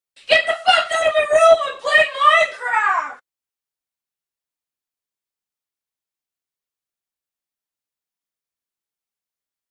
get-the-fck-outta-my-room-im-playing-minecraft-sound-effects.mp3